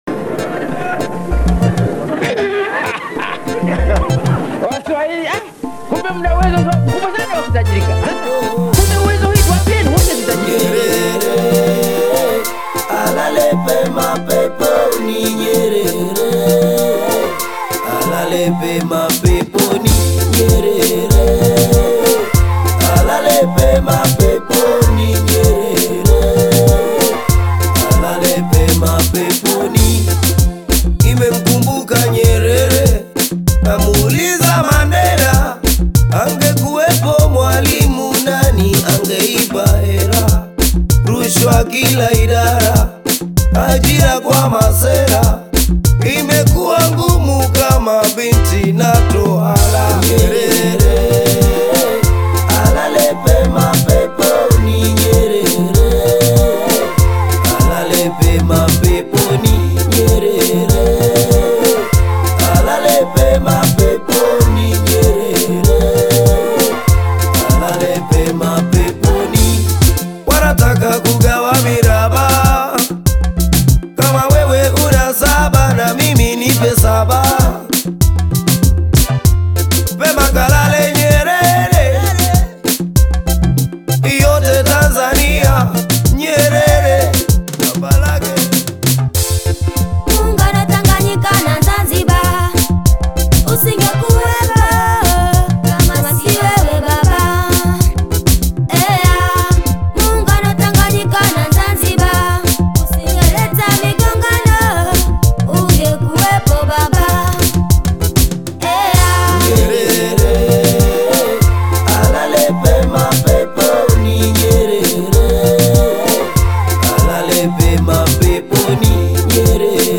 Bongo Fleva Kitambo